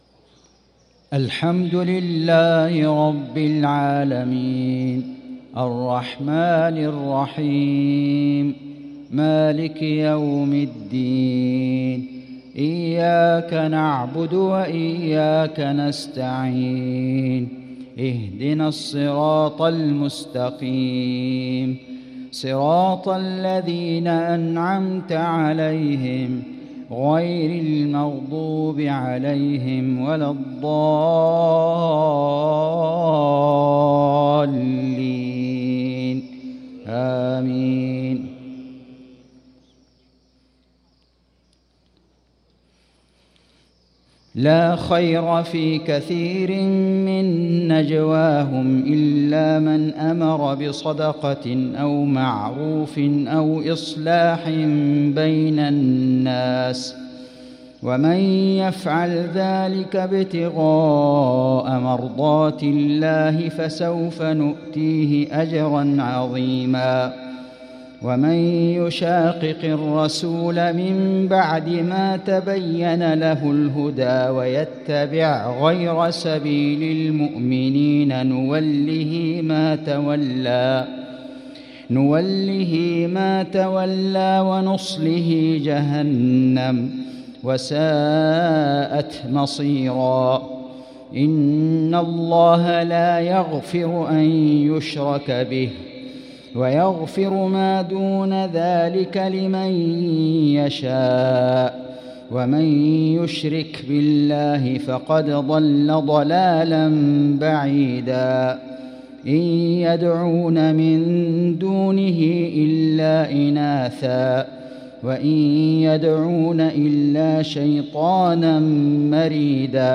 صلاة العشاء للقارئ فيصل غزاوي 16 شعبان 1445 هـ
تِلَاوَات الْحَرَمَيْن .